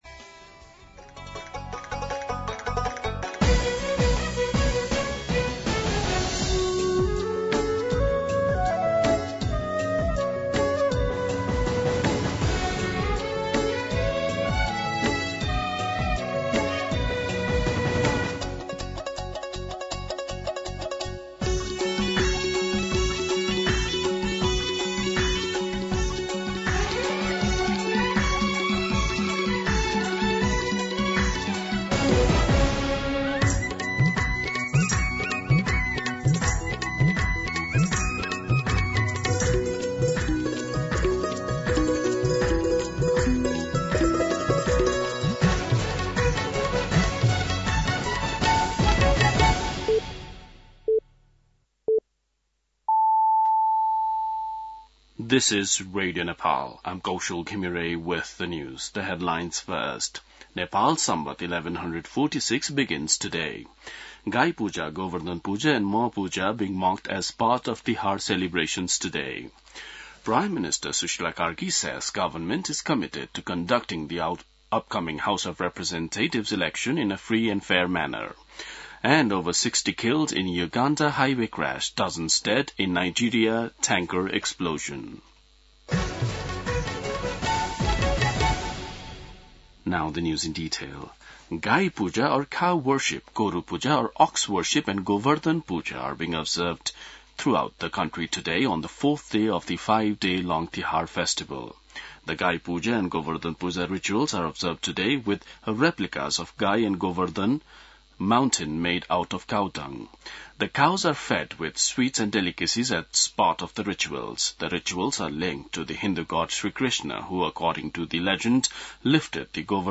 दिउँसो २ बजेको अङ्ग्रेजी समाचार : ५ कार्तिक , २०८२
2pm-English-News-05.mp3